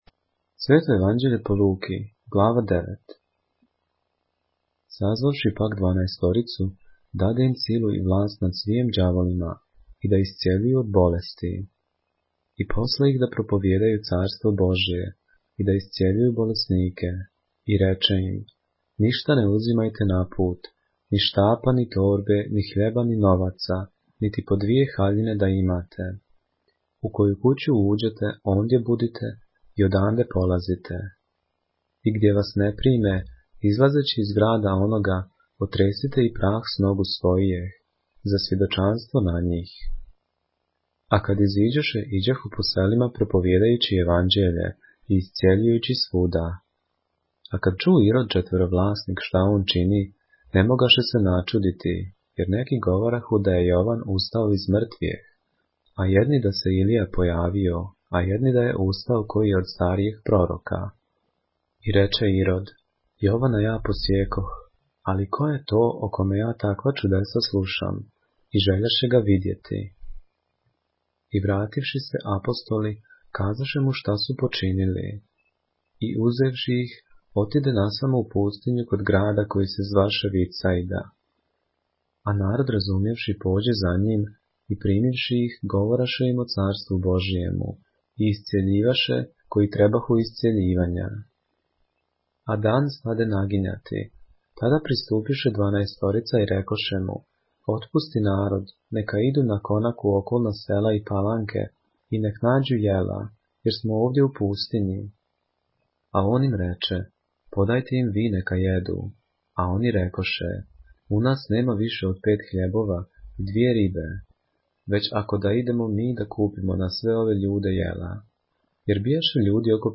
поглавље српске Библије - са аудио нарације - Luke, chapter 9 of the Holy Bible in the Serbian language